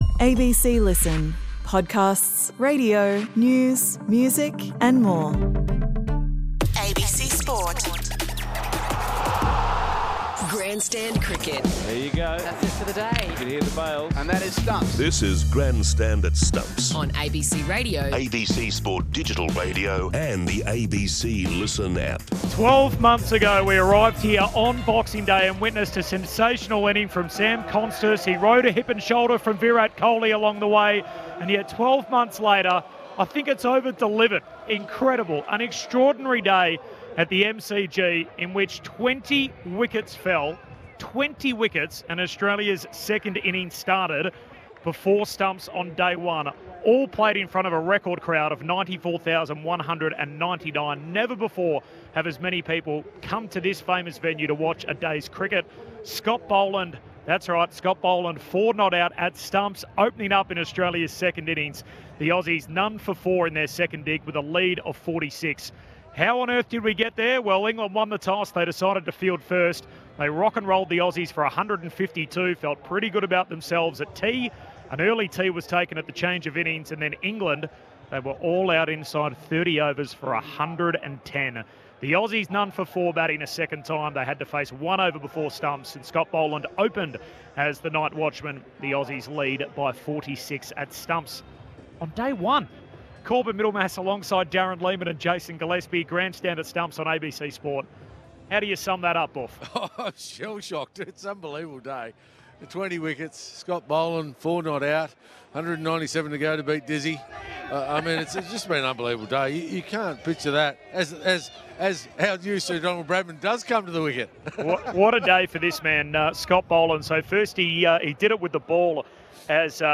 The best interviews, conversations and coverage from ABC Sport across the country.